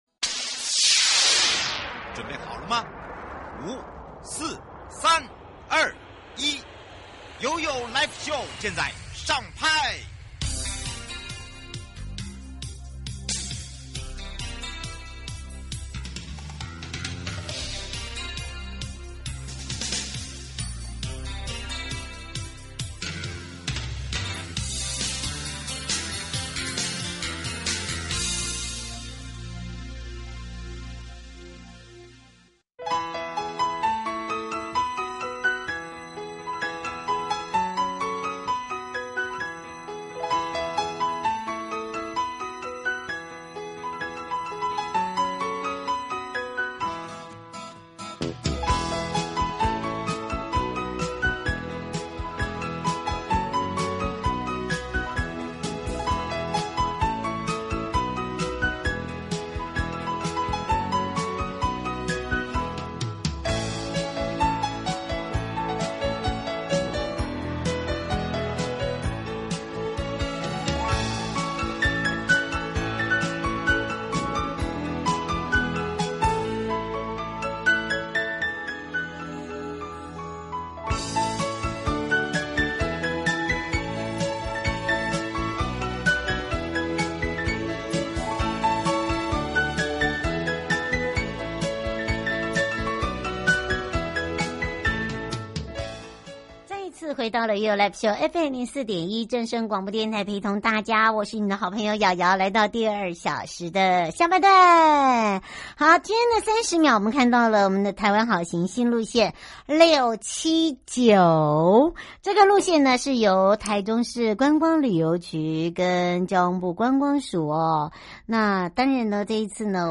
節目內容： 我愛西拉雅~~來趟藝術之旅 「山水之羽」藝術牆正式亮相 彩繪屬於你的Q版「山水之羽」 受訪者： 西拉雅管理處 許主龍處長